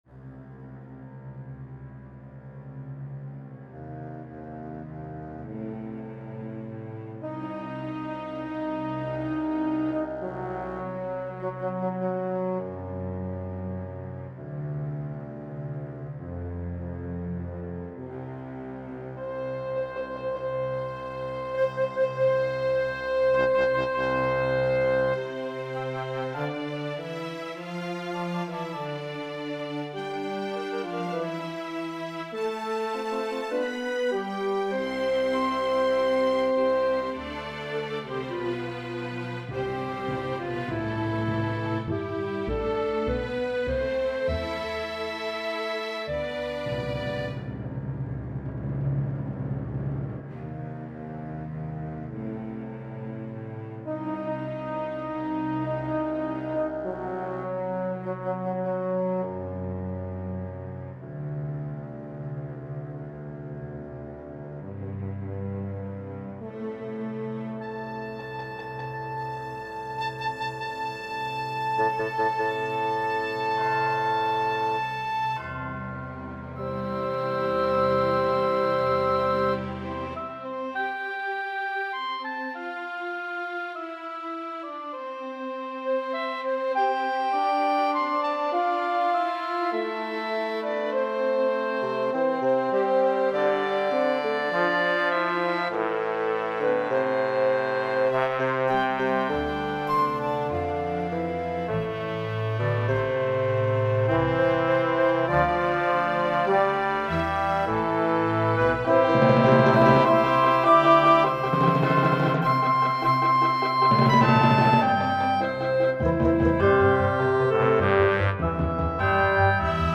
Demoaufnahme
Interstellaris Op 18 Tonart: C-Dur / atonal / Tempo: Andante con moto